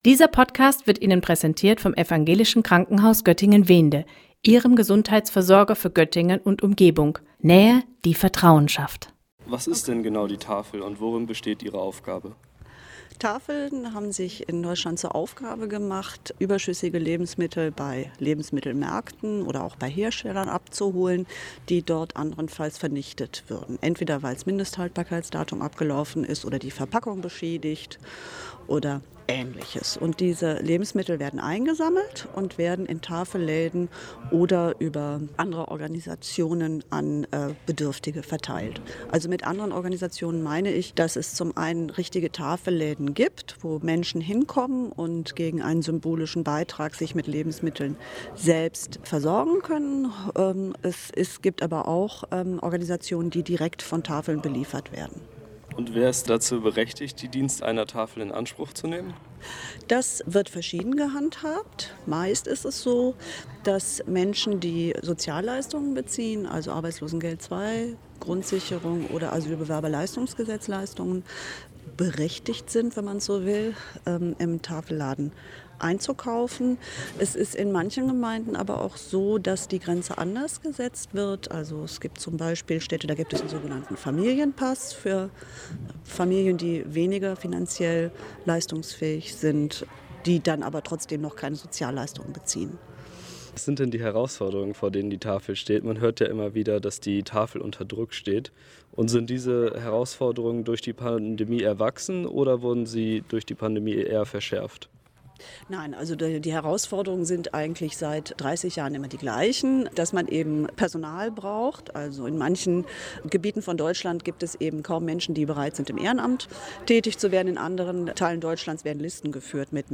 Beiträge > Interview: Die Tafel – Wohlfahrt am Limit - StadtRadio Göttingen